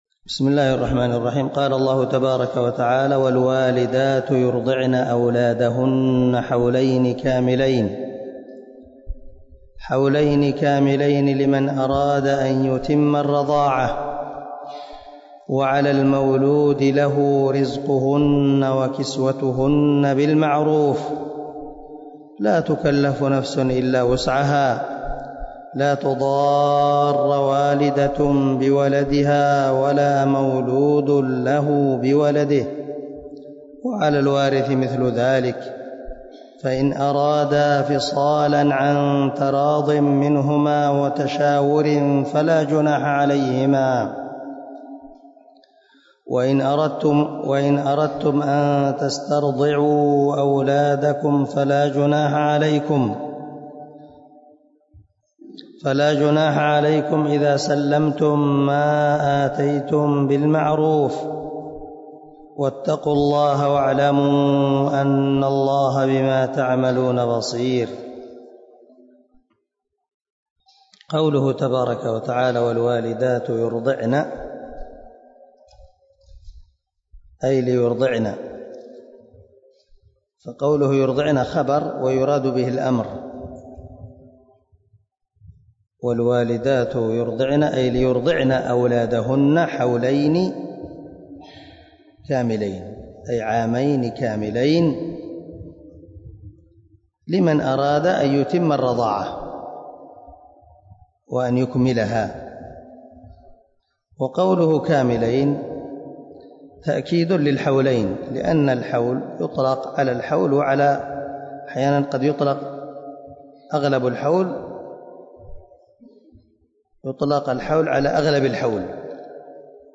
120الدرس 110 تفسير آية ( 233 ) من سورة البقرة من تفسير القران الكريم مع قراءة لتفسير السعدي